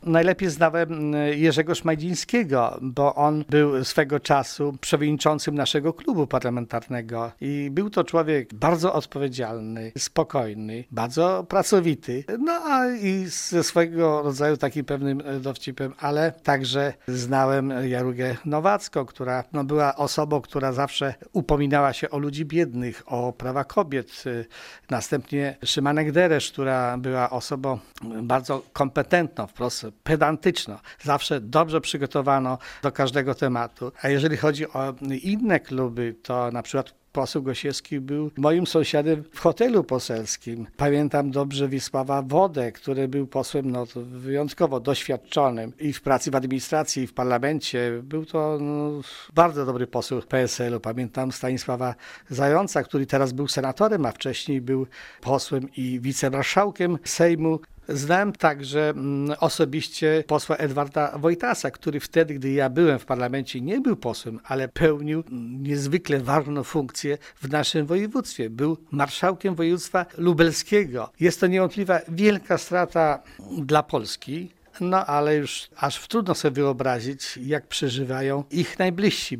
Z wieloma współpracował były poseł III i IV kadencji, a obecny zastępca wójta gminy Łuków Wiktor Osik: